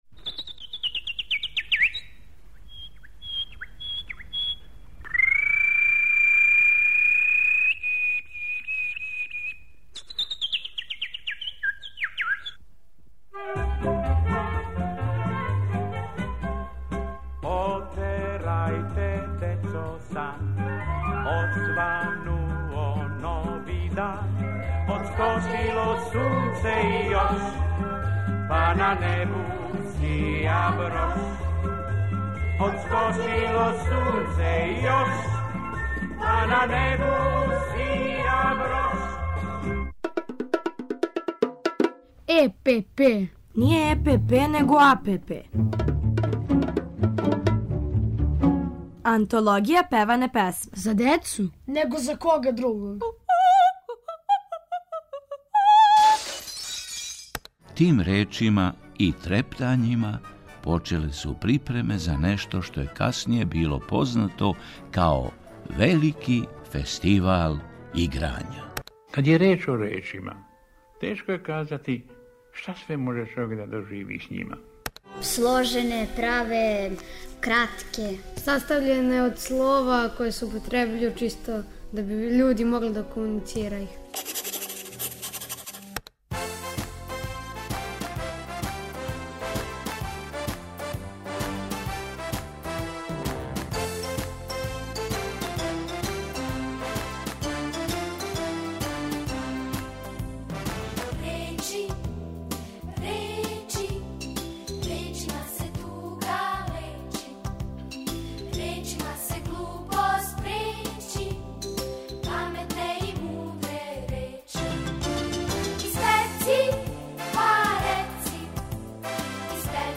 У серијалу АНТОЛОГИЈА ПЕВАНЕ ПЕСМЕ певамо и причамо о речима уз Дечију драмску групу Радио Београда, Колибре... а где су речи, ту је и Иво Андрић.